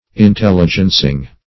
Search Result for " intelligencing" : The Collaborative International Dictionary of English v.0.48: Intelligencing \In*tel"li*gen*cing\, a. Informing; giving information; talebearing.
intelligencing.mp3